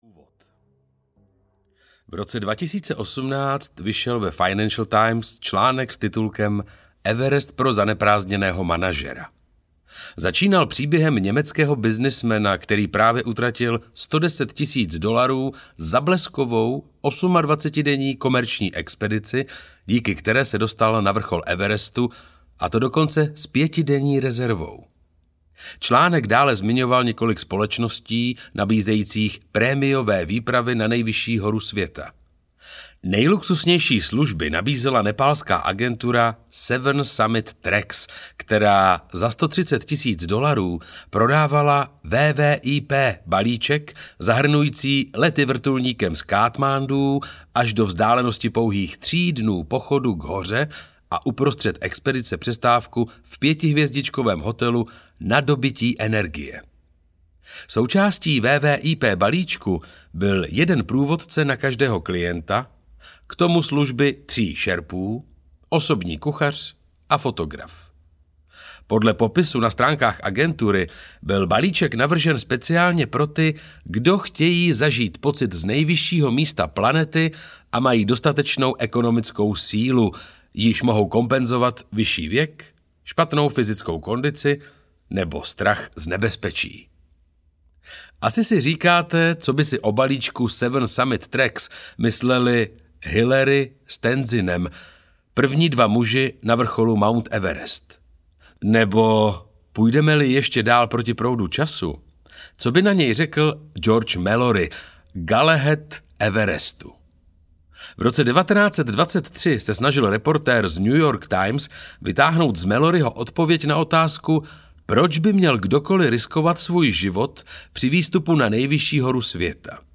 Everest 1922 audiokniha
Ukázka z knihy